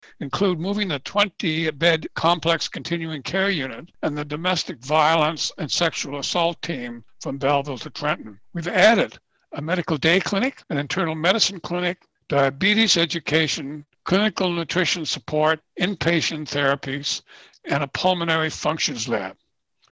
a presentation to Quinte West Council on Monday night